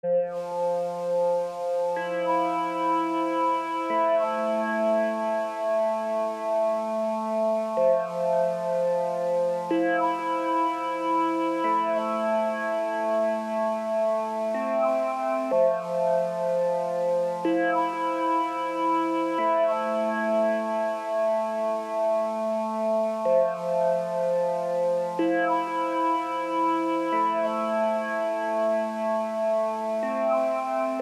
Звук во вступлении, такой протяжный.